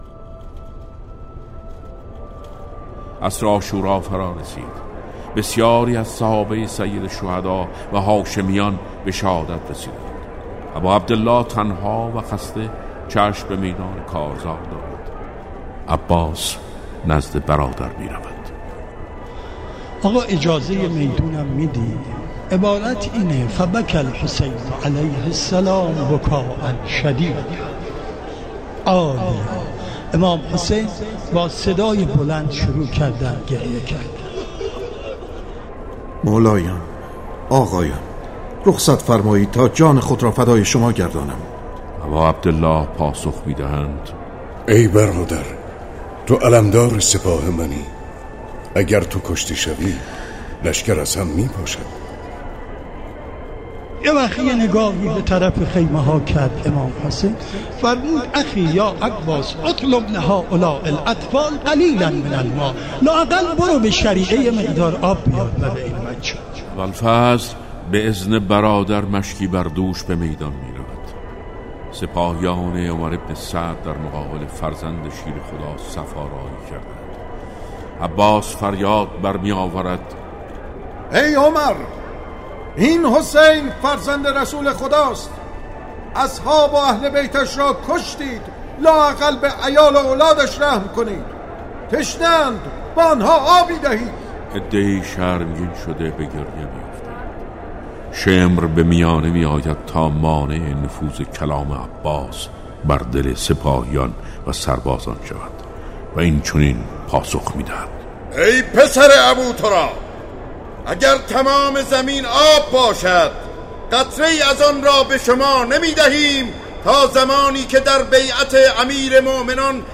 مجموعه ای از روایت خوانی ها